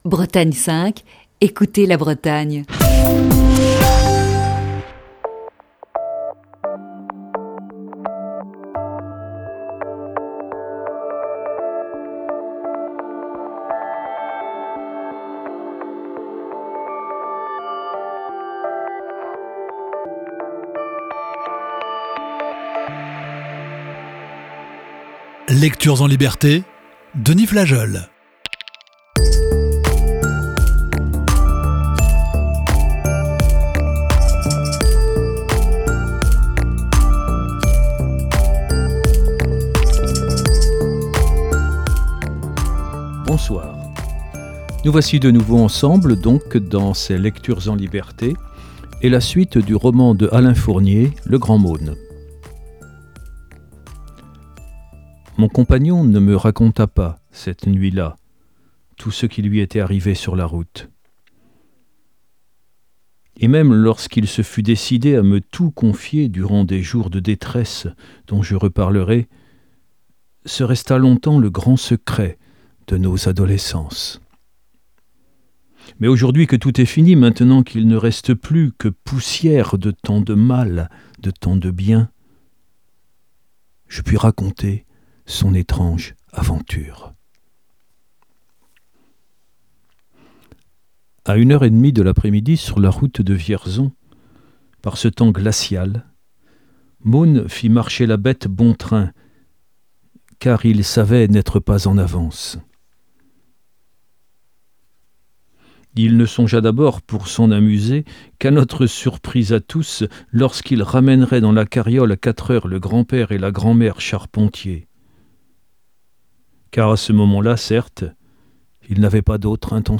Émission du 15 janvier 2020.